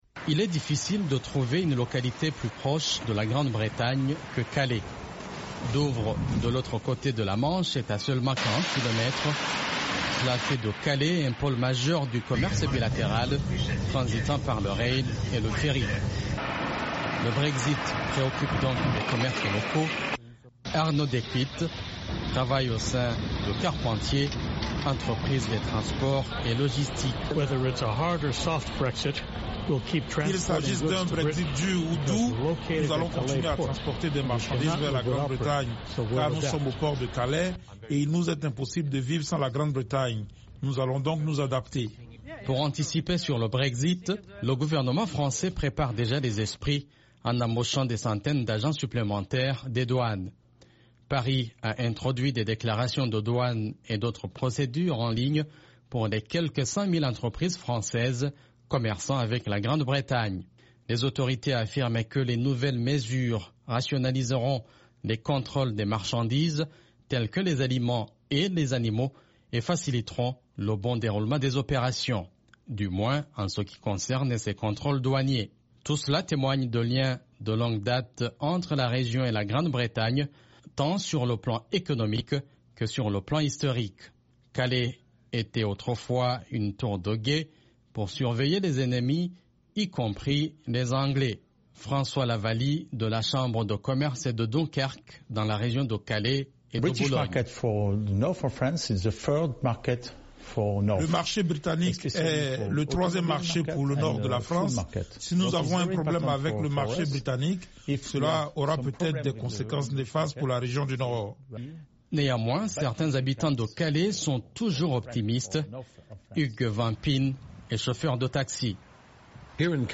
La Grande-Bretagne reste figée sur le Brexit, mais de l’autre côté de la Manche, la France est très attentive. Elle teste de nouvelles mesures douanières ce mois-ci pour éviter d'éventuels obstacles en anticipant un scénario de "deal" ou de "no-deal", à savoir une sortie de l’Union Européenne avec ou sans accord avec Bruxelles. Reportage à Calais